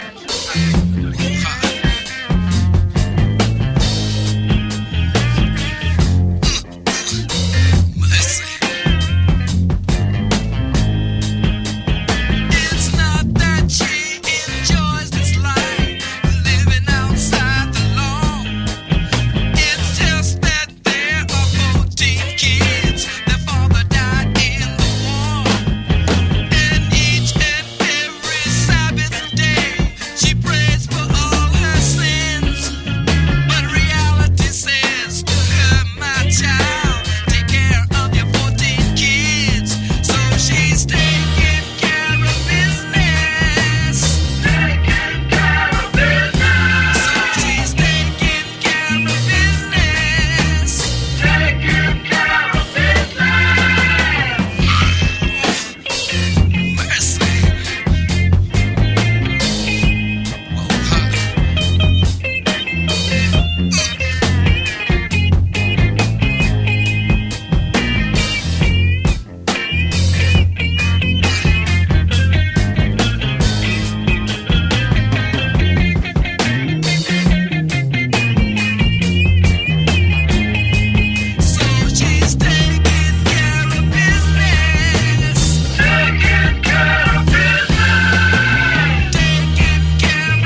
[ FUNK | SOUL ]